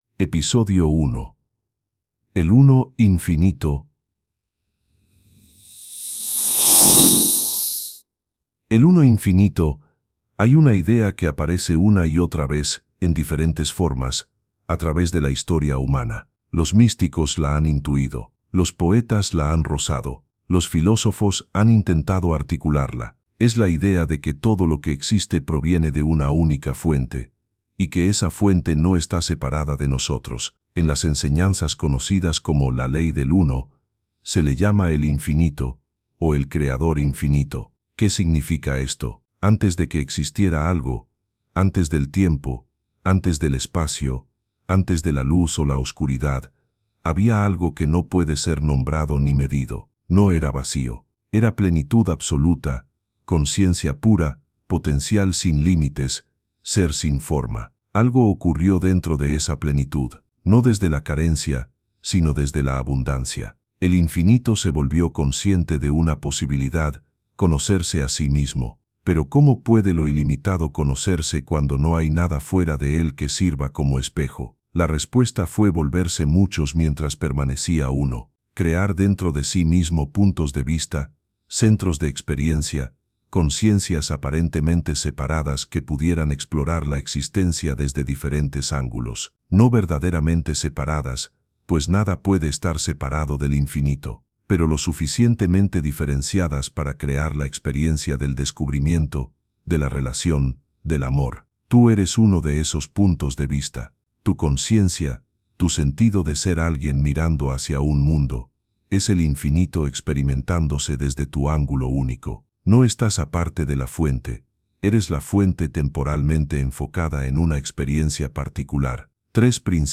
ensenanzas-audiolibro-completo.mp3